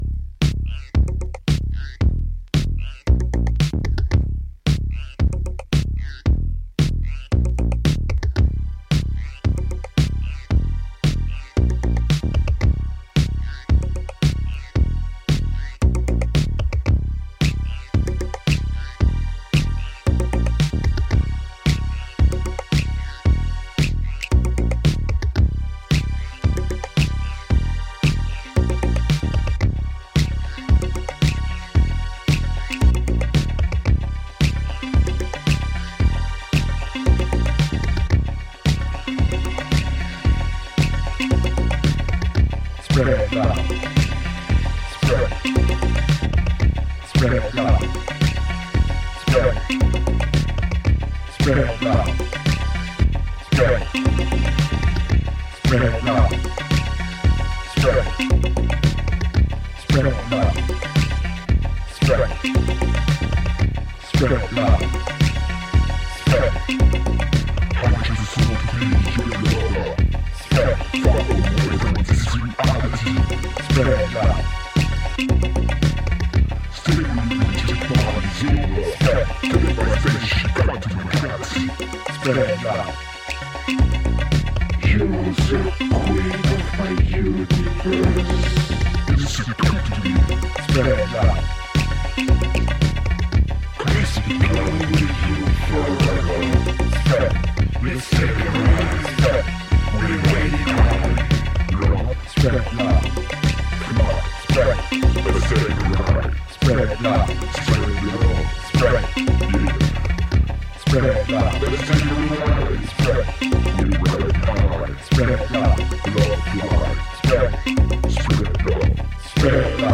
Bazaar mutant disco
de-tuned downtown funk rider
Disco House Boogie